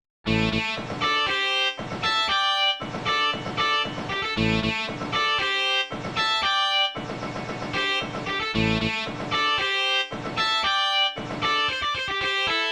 MIDI-Audio-Datei
Funky Guitar